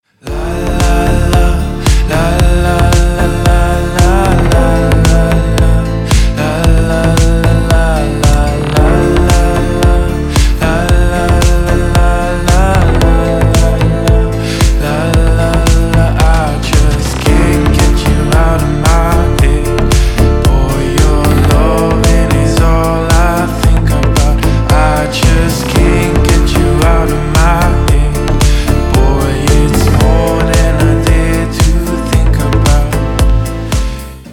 Отрывки спокойных мотивов
• Песня: Рингтон, нарезка